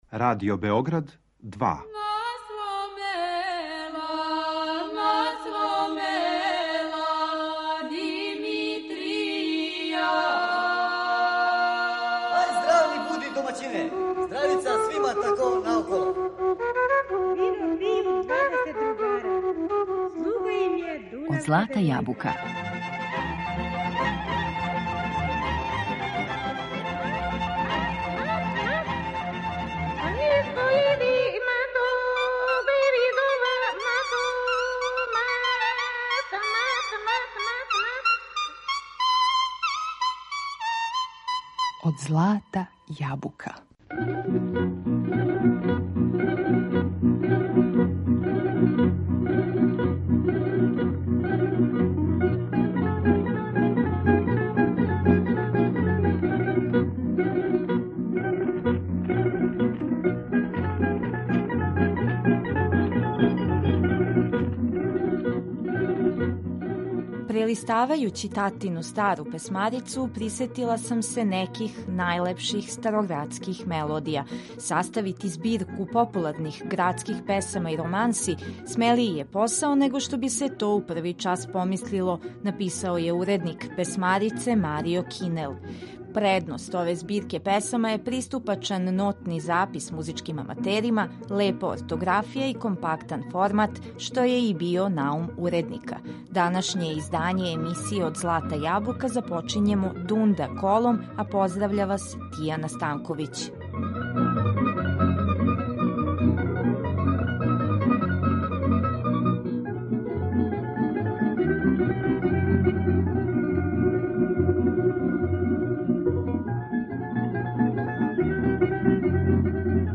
Староградске песме и романсе